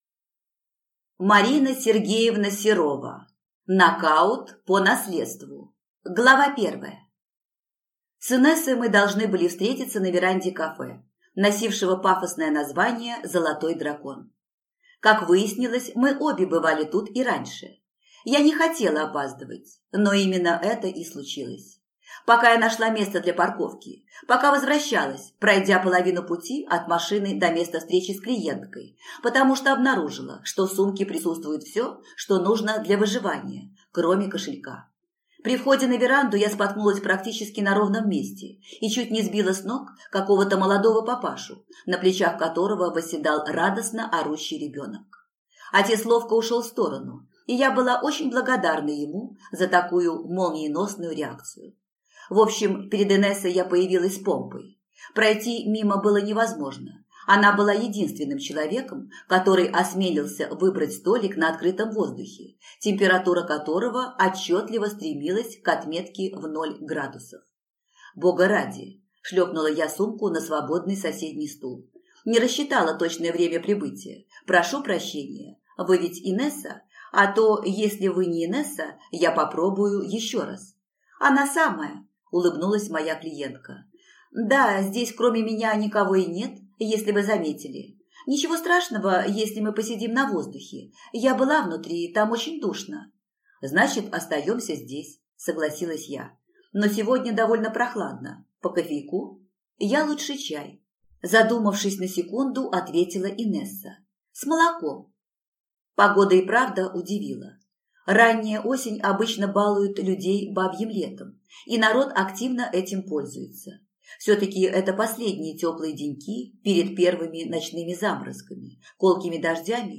Аудиокнига Нокаут по наследству | Библиотека аудиокниг
Прослушать и бесплатно скачать фрагмент аудиокниги